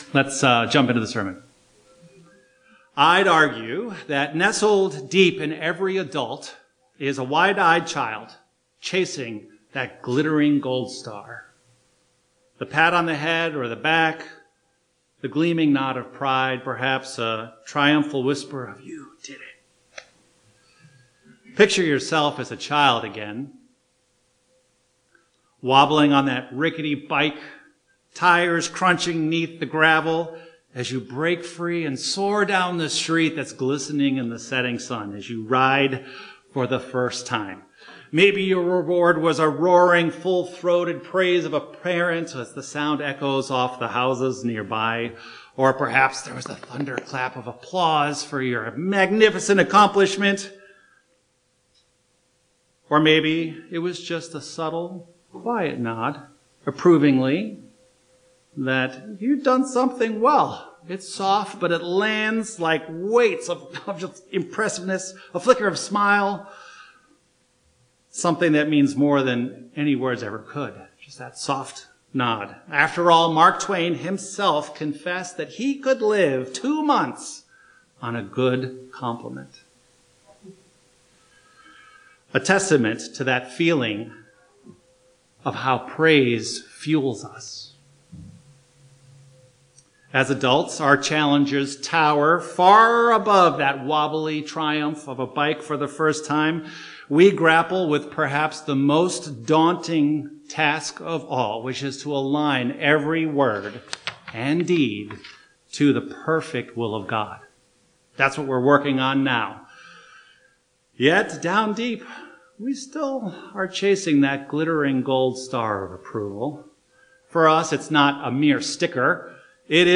Sermon
Given in Northwest Indiana